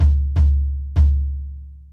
快速时髦的爵士鼓 Bop Kick 125 Bpm
Tag: 125 bpm Jazz Loops Drum Loops 330.92 KB wav Key : Unknown